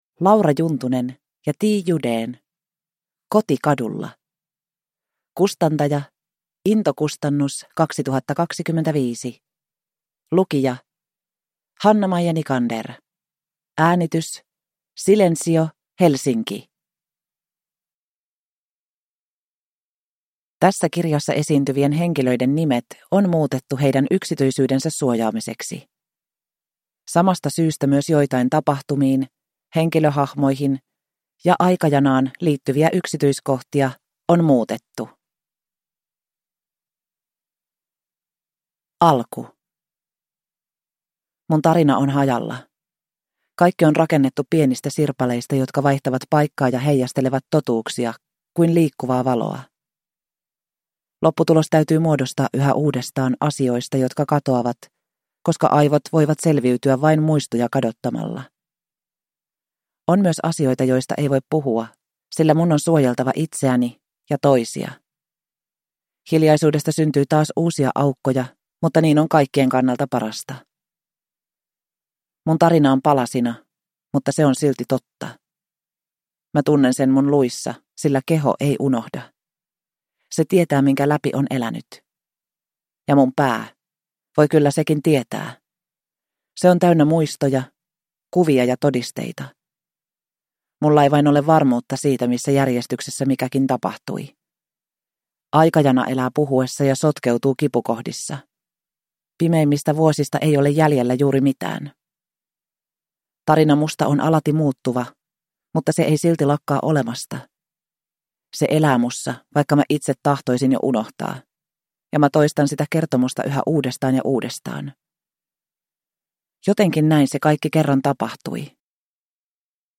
Koti kadulla (ljudbok) av Laura Juntunen